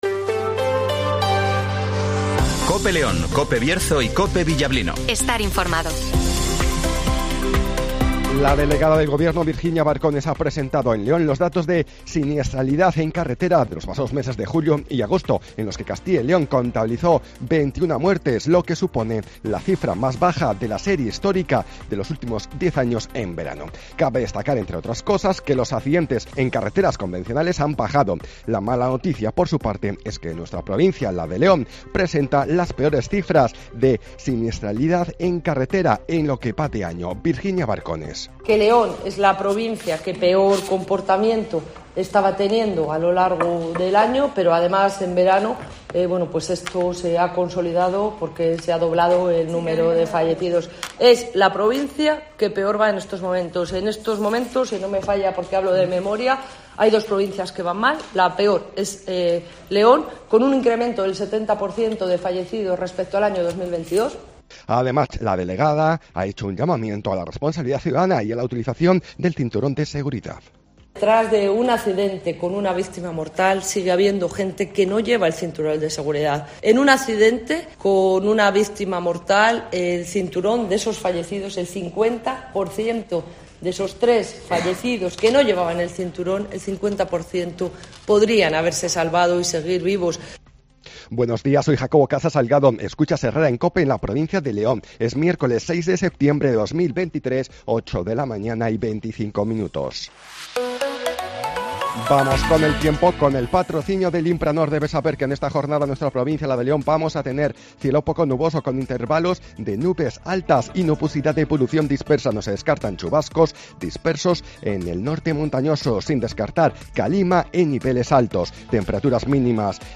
- Informativo Matinal 08:24 h